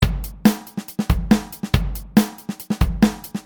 Make a simple drum beat in an empty pattern.
One or two bars will be enough.